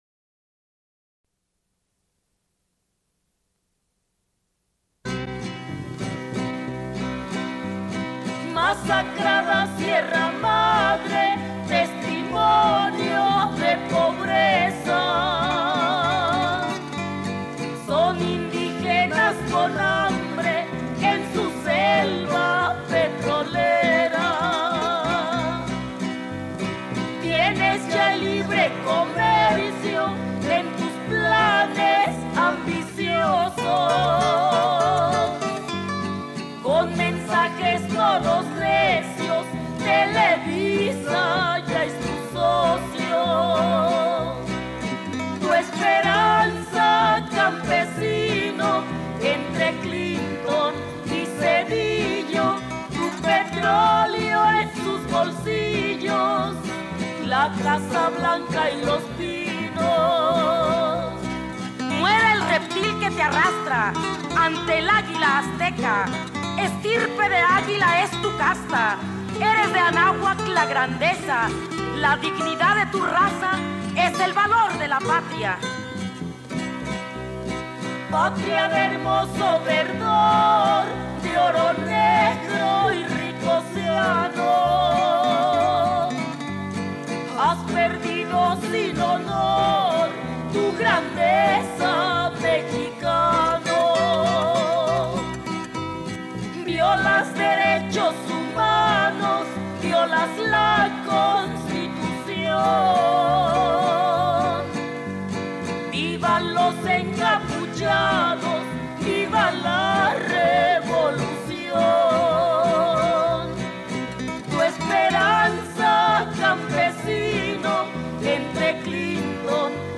Suite de l'émission : des nouvelles du Chiapas en direct
Radio